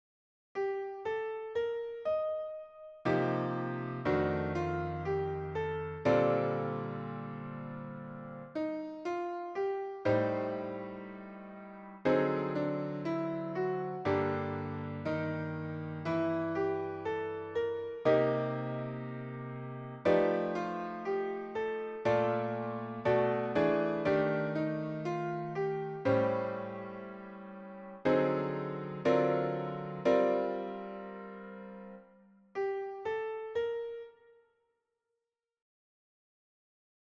Improvisation Piano Jazz
Mise en pratique et enchainements II V I